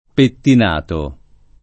pettinato [ pettin # to ]